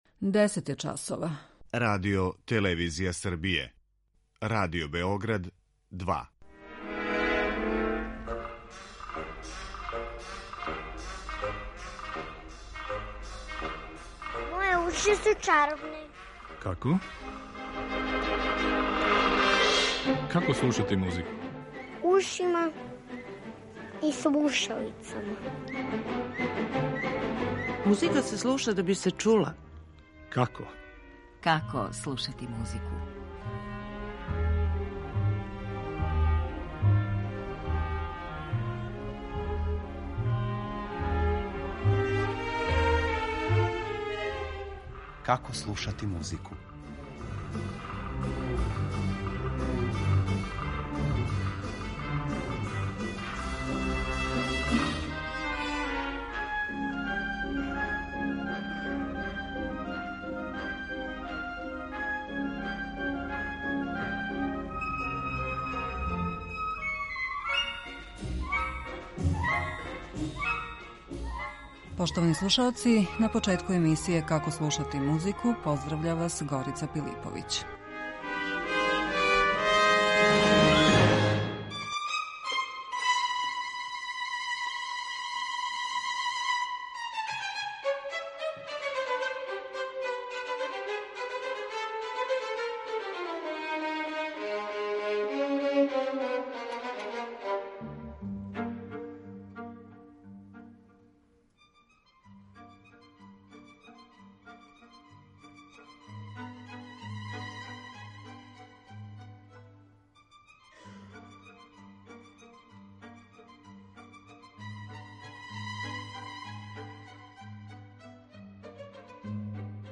У првој емисији циклуса говорићемо о Шостаковичевим формативним годинама и основним одликама његовог стила. Одабране музичке примере пратиће и његове речи, забележене на грамофонским плочама.